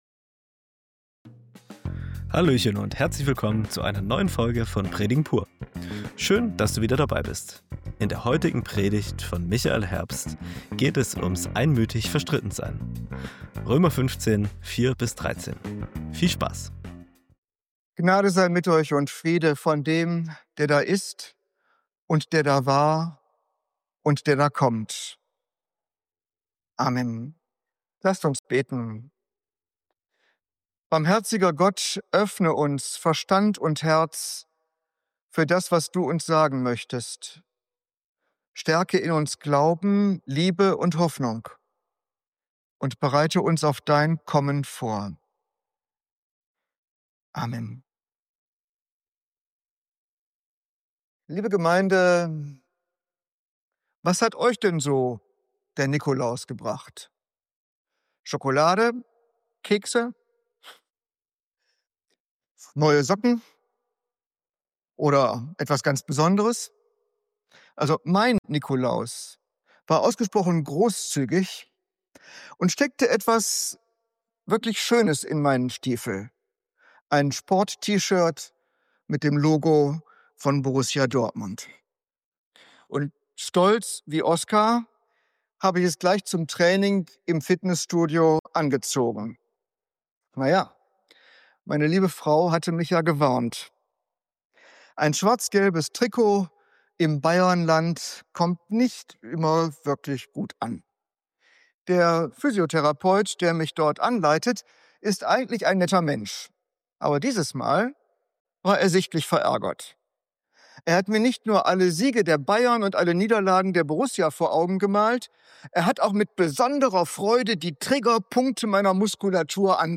Predigt Pur: Röm 15,4-13 ~ Predigen Podcast